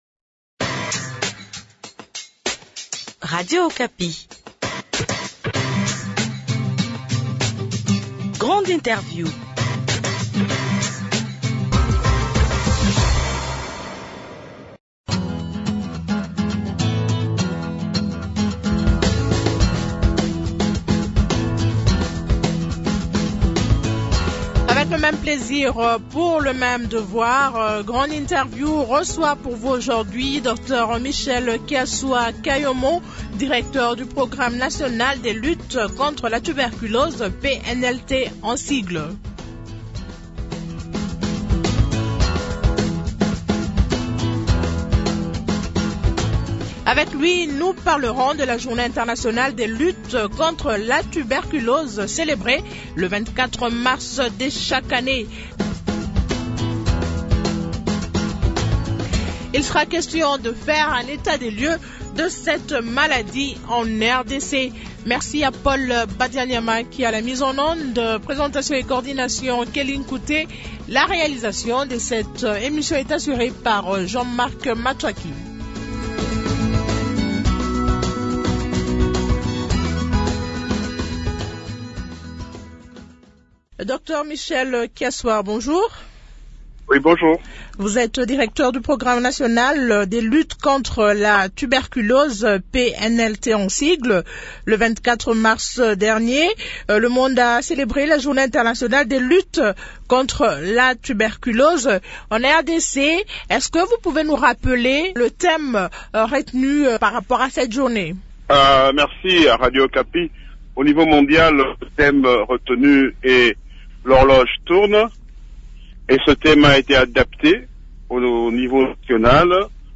cet entretien